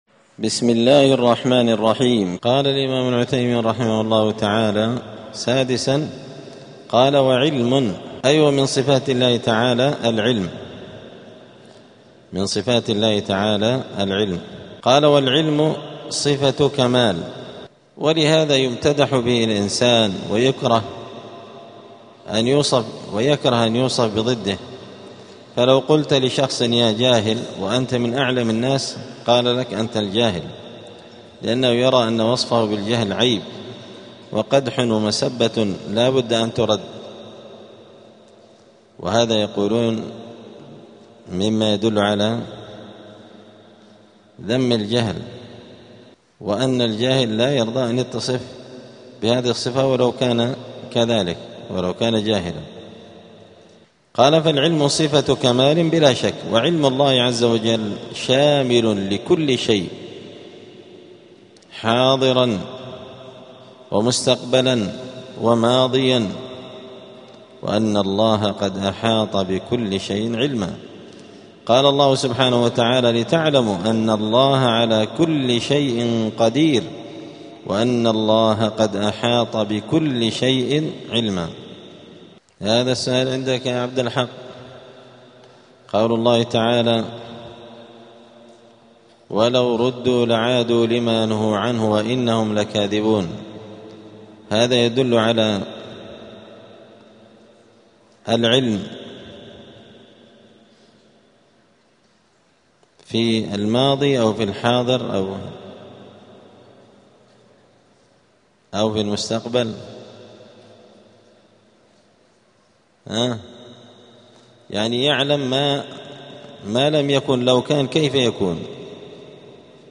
دار الحديث السلفية بمسجد الفرقان قشن المهرة اليمن